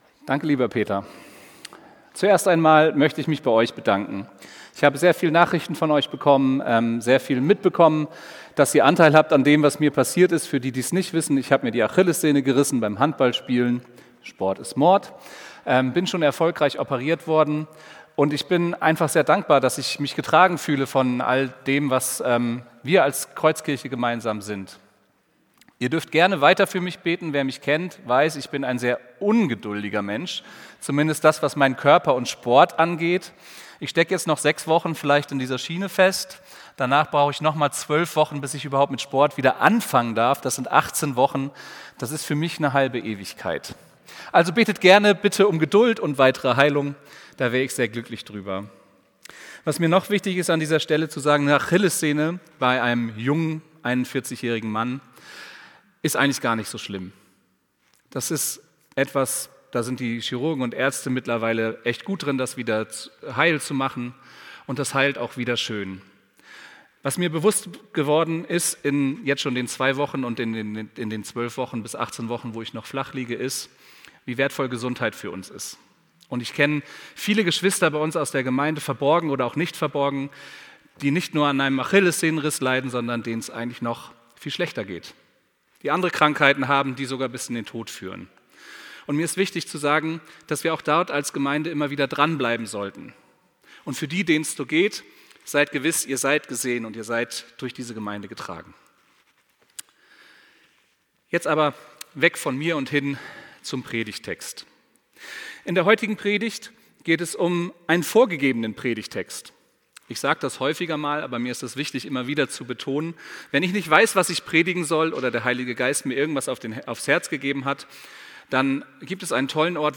Predigt vom 25.01.2026